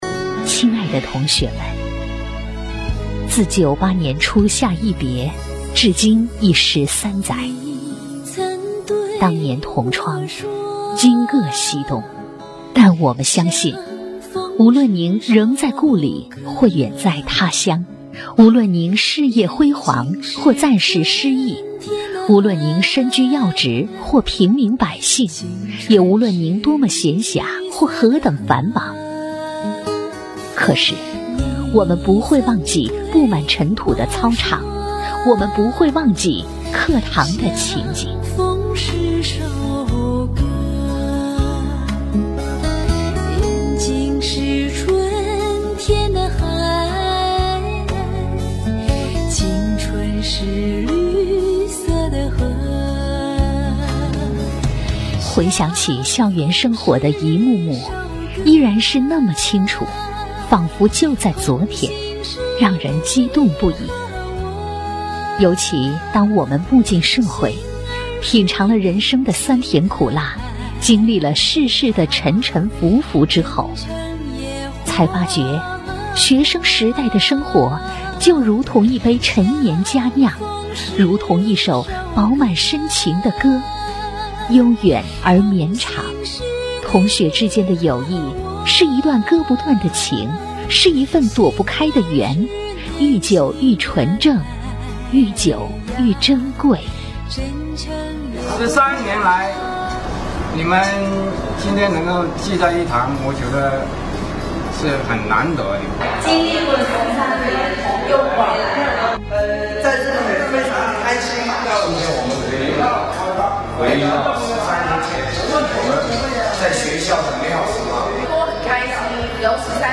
• 女S114 国语 女声 宣传片 中职一班毕业13周年纪念-_标清 亲切甜美